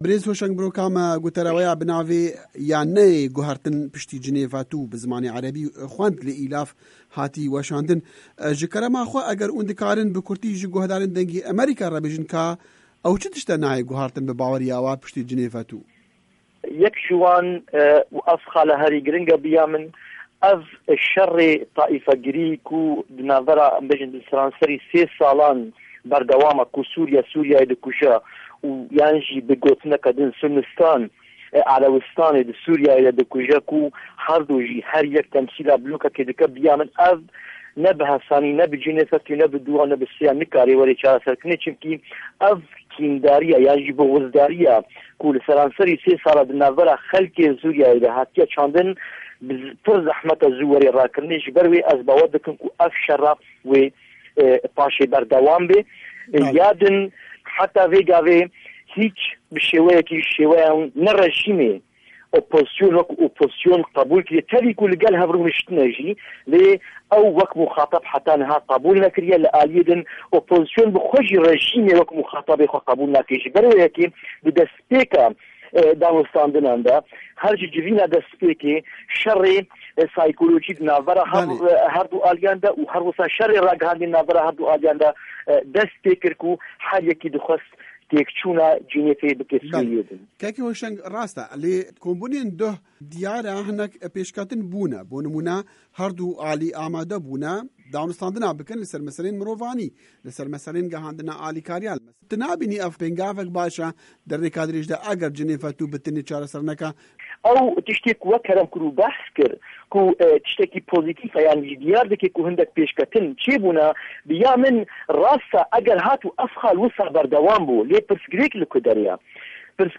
Hevpeyivin